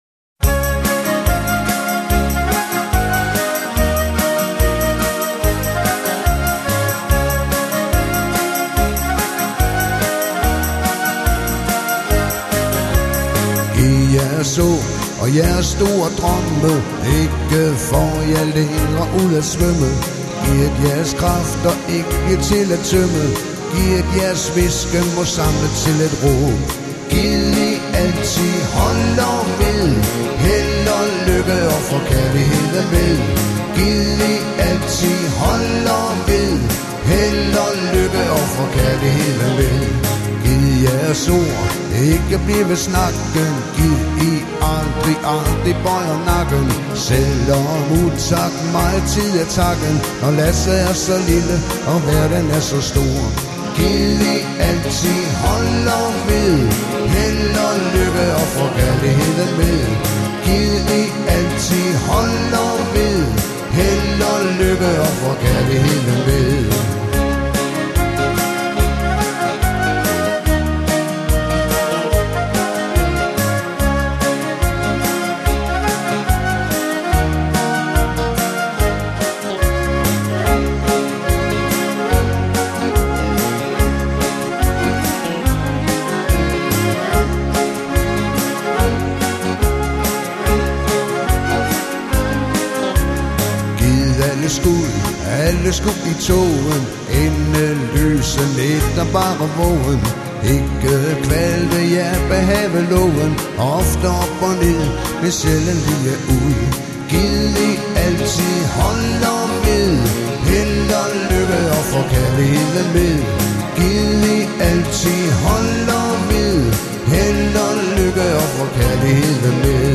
Professionelt danse og hyggemusik til alle aldersgrupper.